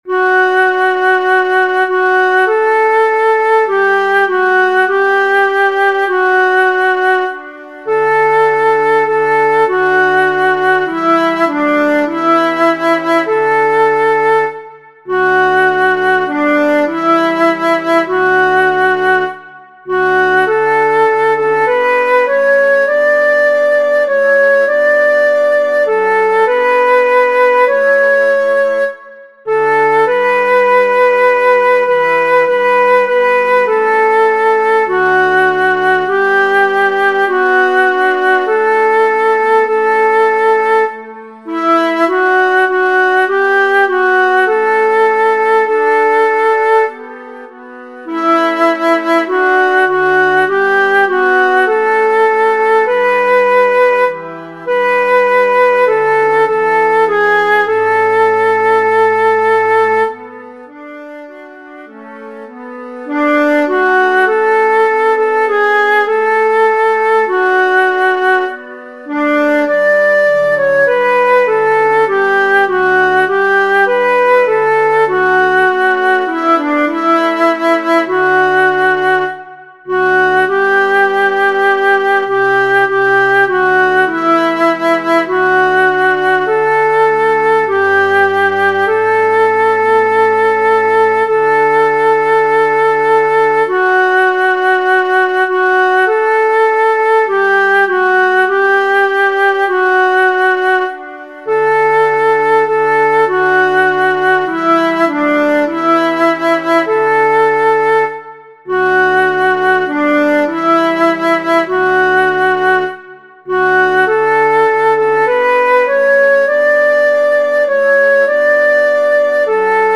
Para aprender la melodía podéis utilizar estos enlaces instrumentales en formato MP3:
Pan divino S MIDIDescarga
pan-divino-s-midi.mp3